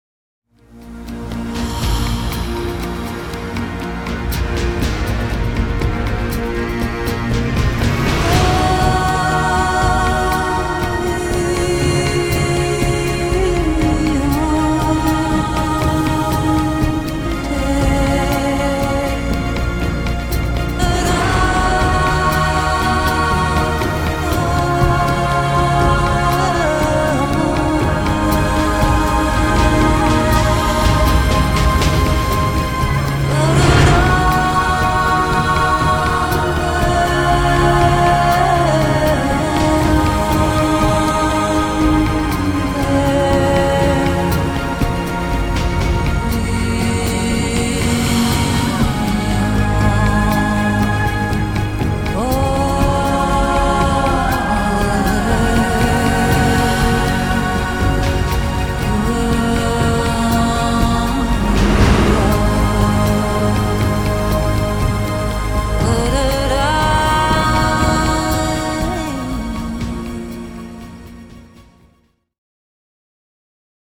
All Songs Mixed & Mastered by:
haunting and dramatic piece with signature vocals